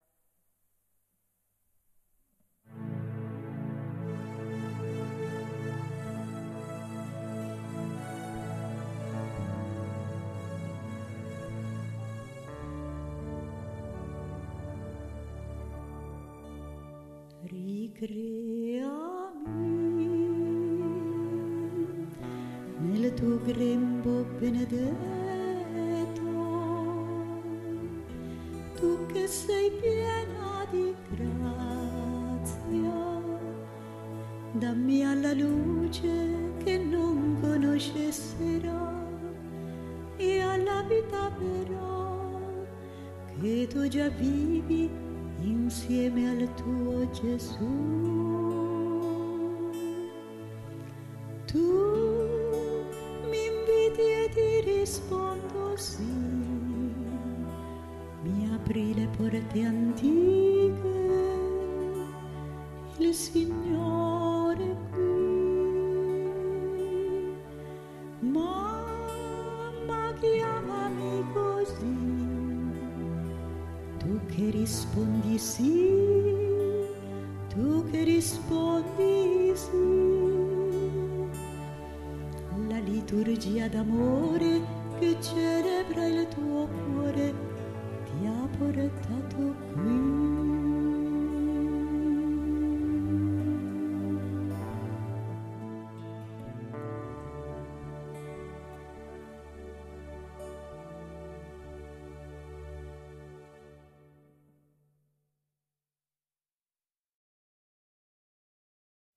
Canto alla Vergine canto mp3 –